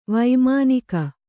♪ vaimānika